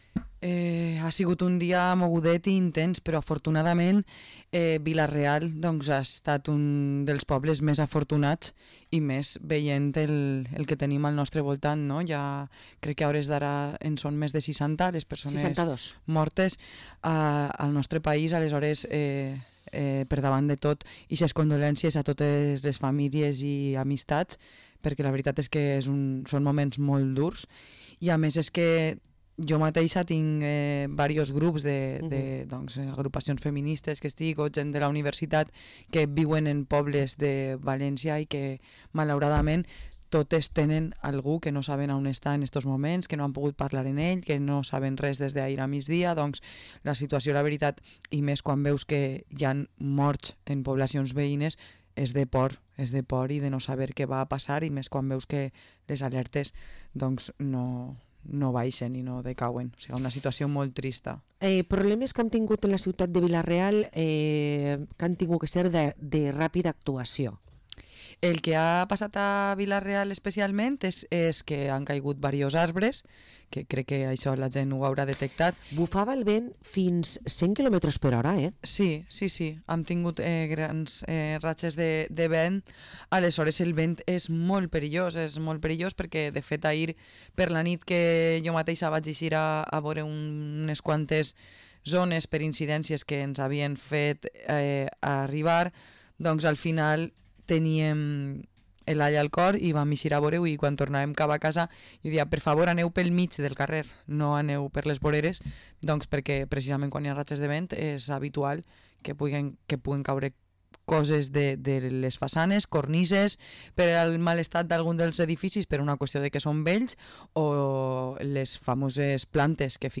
María Fajardo, regidora de Serveis Públics, ens compta la situació actual al municipi